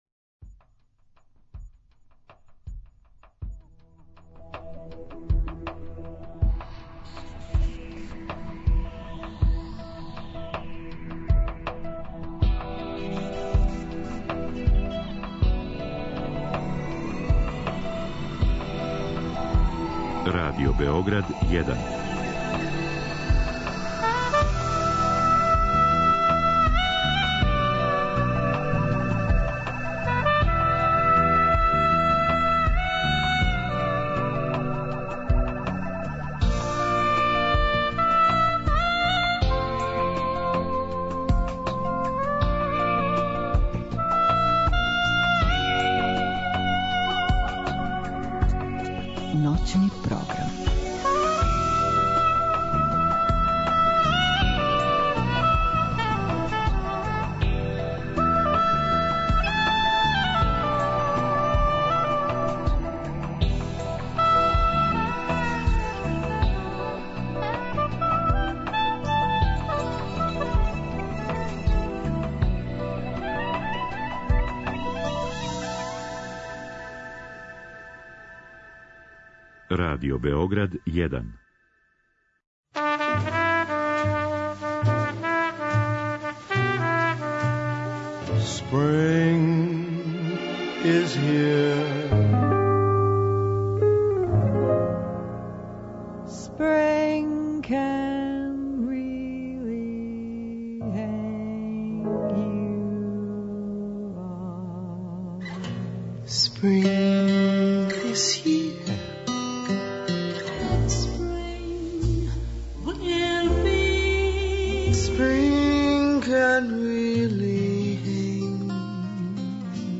То је управо ова ноћ када се казаљке у 2 – померају један сат унапред те наша емисија траје само 3 сата; а у програму – џезери свирају класику.